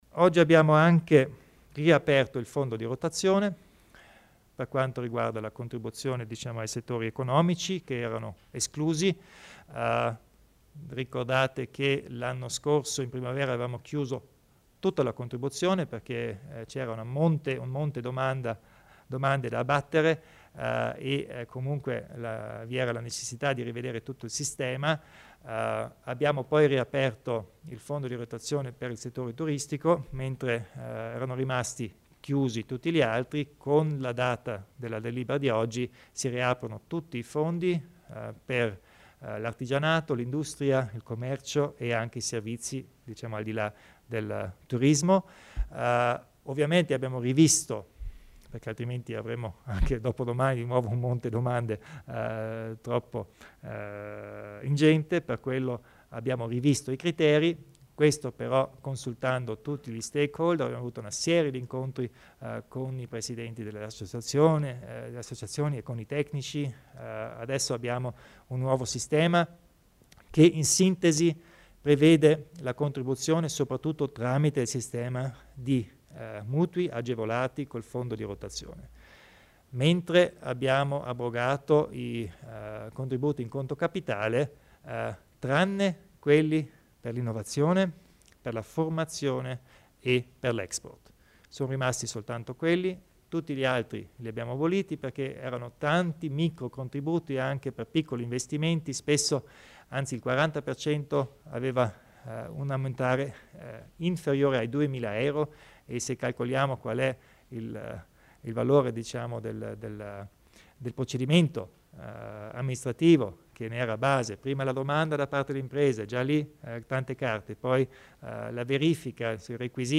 Il Presidente Kompatscher spiega le novità in tema di fondo di rotazione